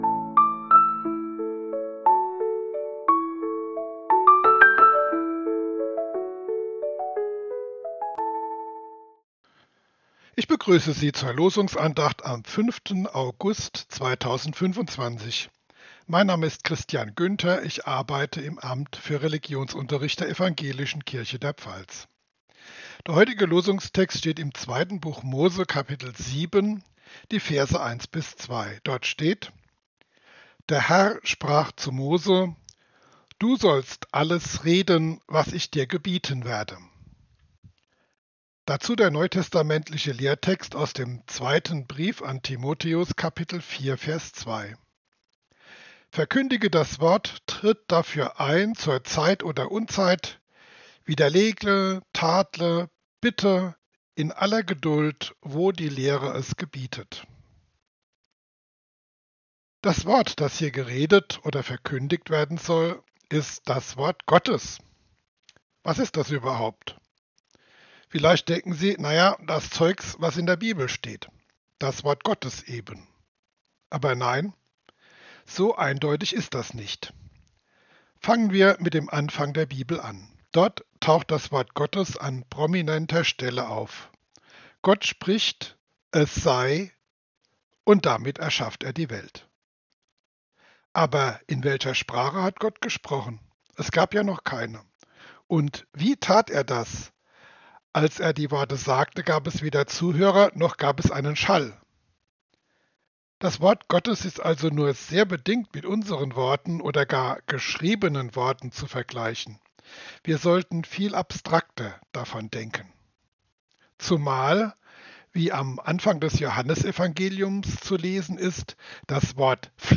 Losungsandacht für Dienstag, 05.08.2025